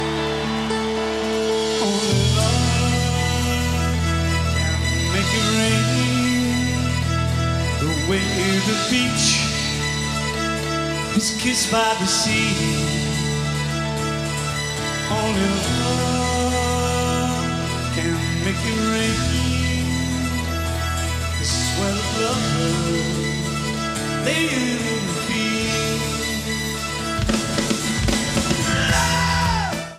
Pre-FM Radio Station Reels